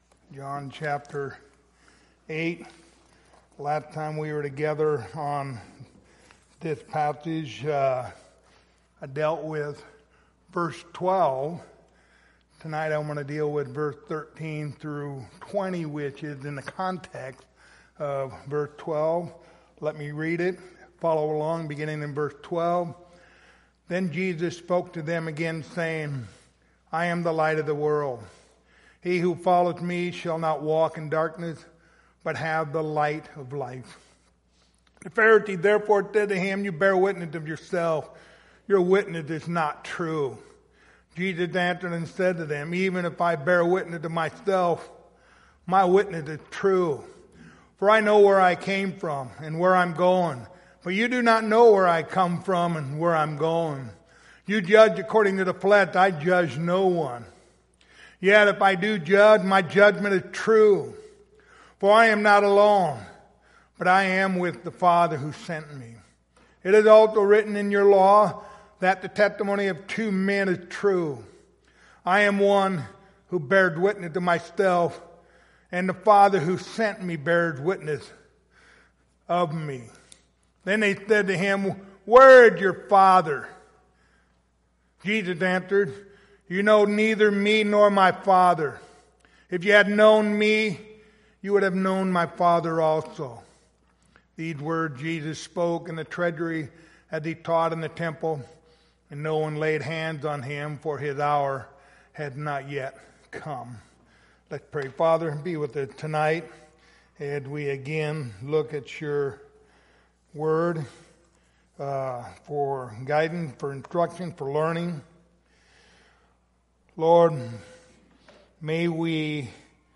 Passage: John 8:13-20 Service Type: Wednesday Evening